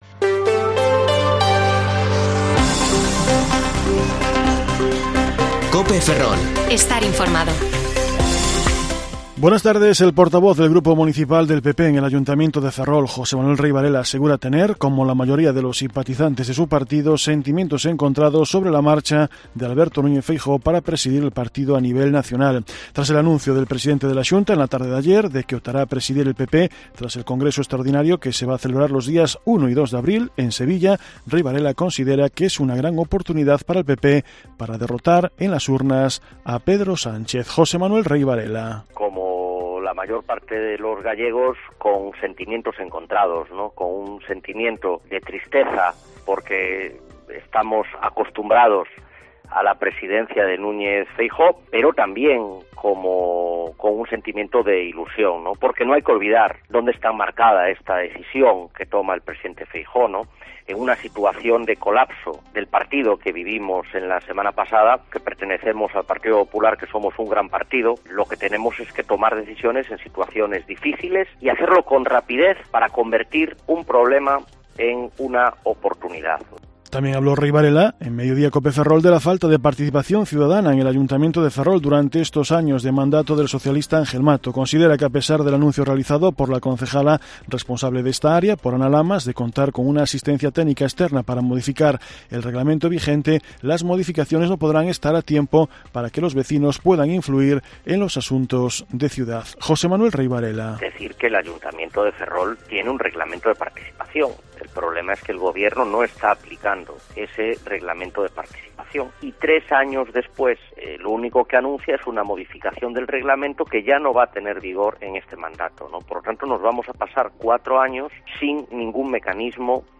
Informativo Mediodía COPE Ferrol 3/3/2022 (De 14,20 a 14,30 horas)